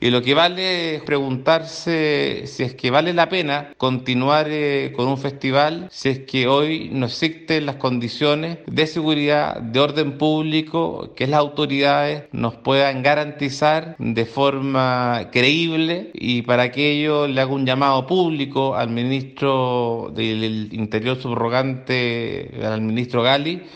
Ya hay reacciones políticas a estos hechos, el diputado de Renovación Nacional, Andrés Celis, quien además es ex concejal de la Viña, emplazó a las autoridades a dar garantías para el desarrollo de las noches que restan del festival, de lo contrario es válido analizar si es viable o no continuar con el desarrollo de este certamen.